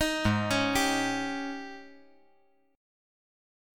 AbM7sus4 Chord
Listen to AbM7sus4 strummed